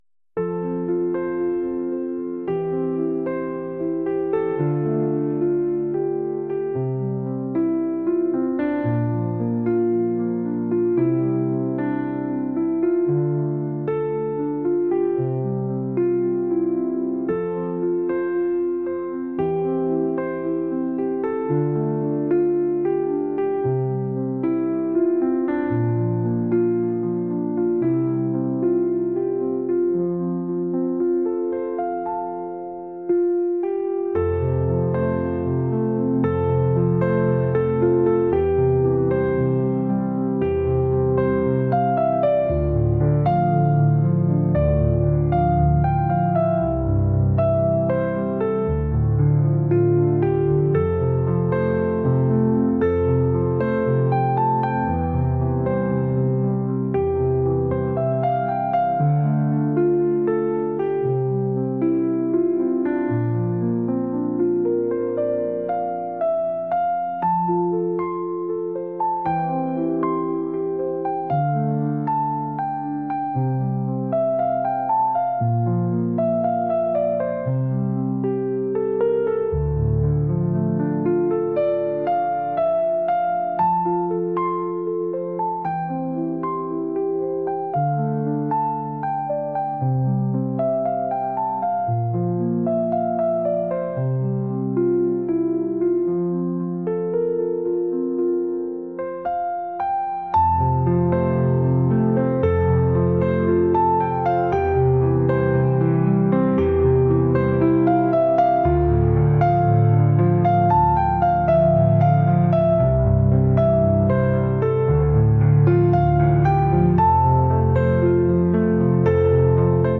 acoustic | pop | ambient